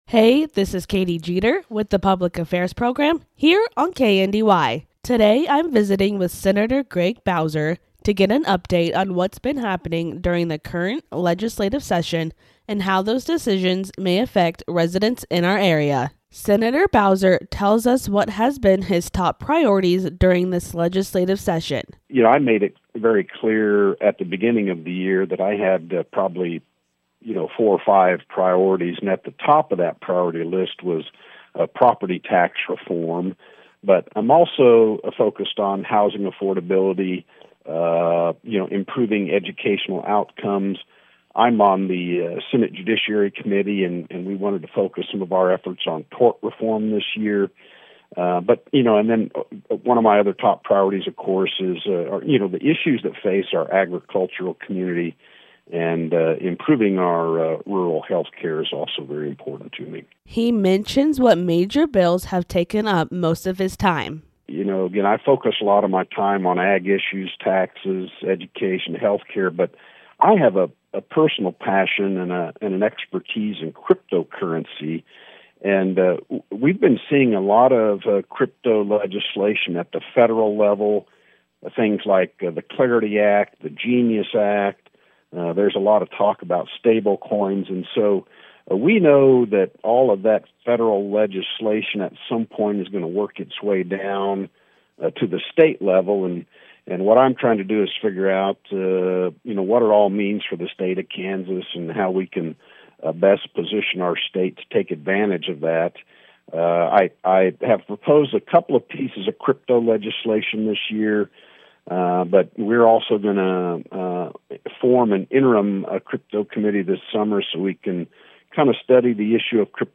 visited with Senator Craig Bowser to discuss his priorities this legislative session, which include strengthening rural communities, supporting education, and ensuring responsible budgeting. Bowser highlighted key issues taking much of his time, including property tax relief efforts and funding allocations that impact schools and local governments. He also noted ongoing concerns from rural areas, particularly access to healthcare, workforce shortages, and maintaining infrastructure in small towns.